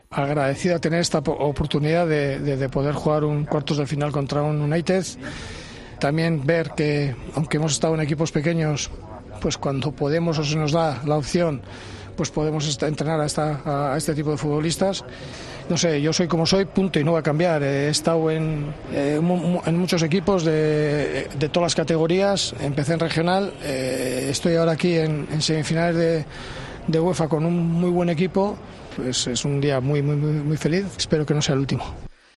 El entrenador del Sevilla habló de la brillante clasificación del equipo para las semifinales de la Europa League tras eliminar al Manchester United.